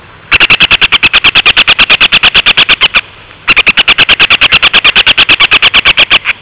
El Vari ceniciento (Circus cinereus),